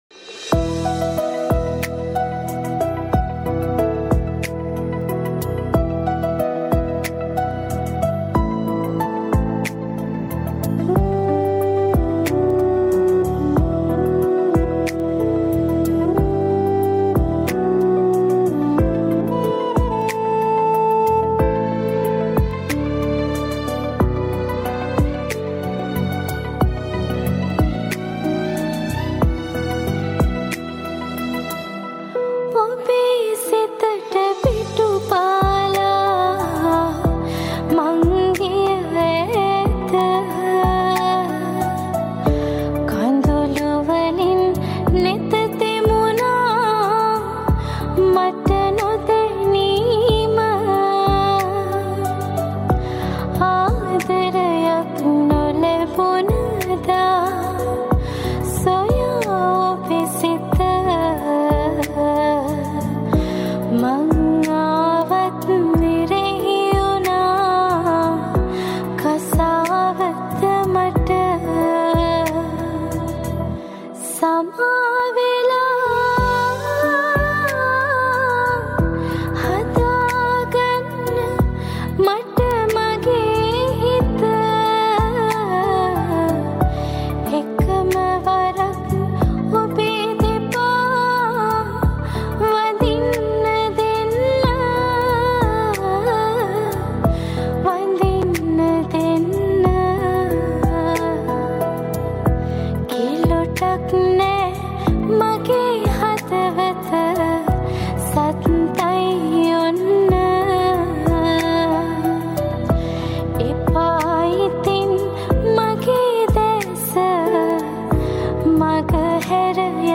Category: New Sinhala Mp3